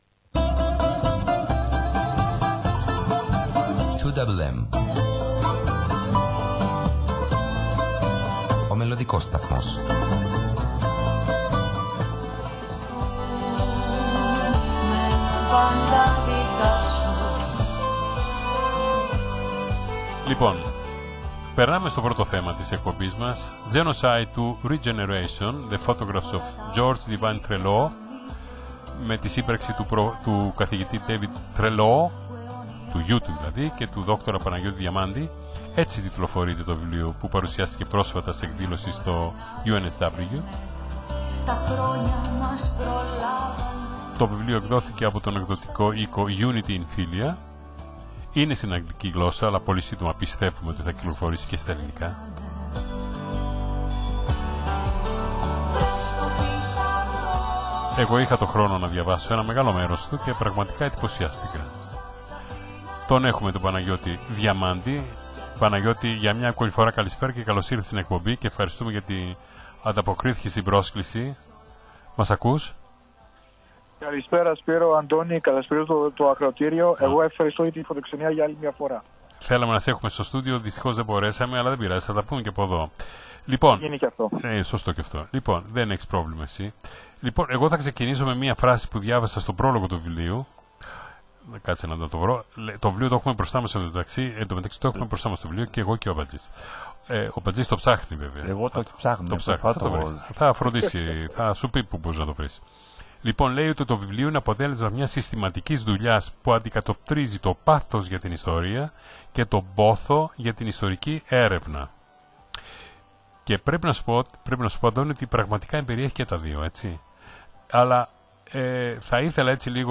συνέντευξης
ελληνόφωνο ραδιοσταθμό του Σίδνει